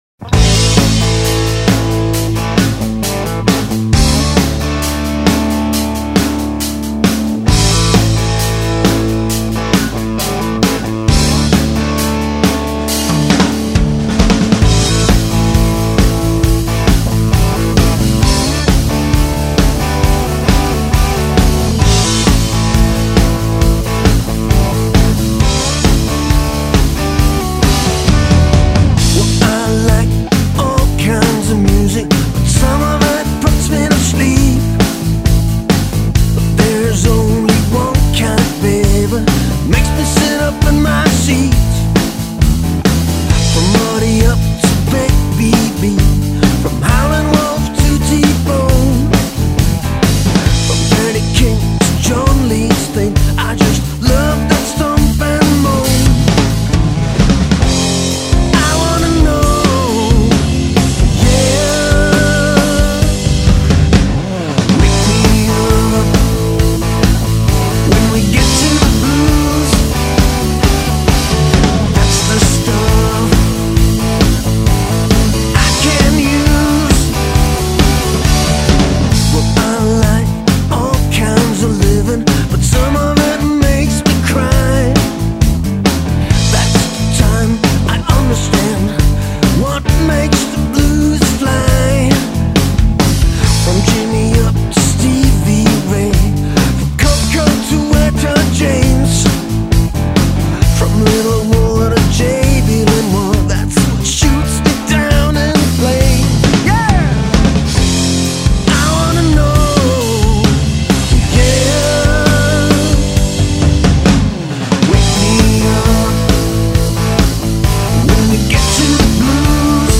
Blues / rock. Singer and guitarist